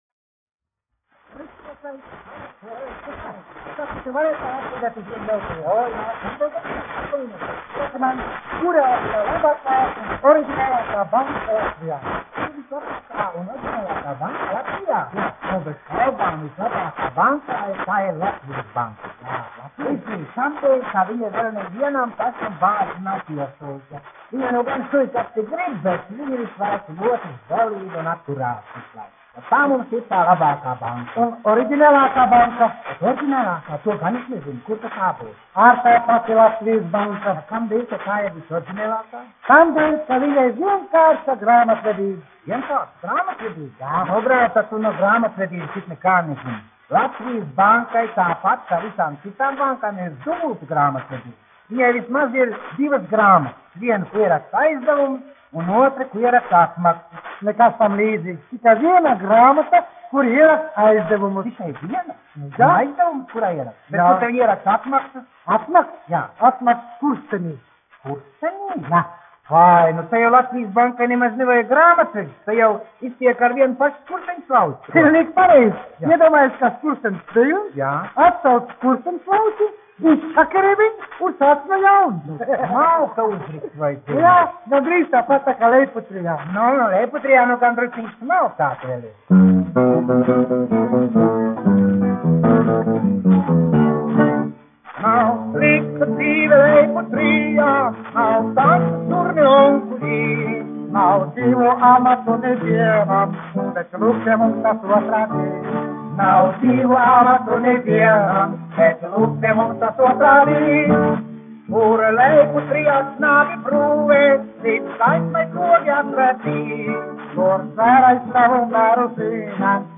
1 skpl. : analogs, 78 apgr/min, mono ; 25 cm
Humoristiskās dziesmas
Ieraksta sākumā bojāta skaņa
Latvijas vēsturiskie šellaka skaņuplašu ieraksti (Kolekcija)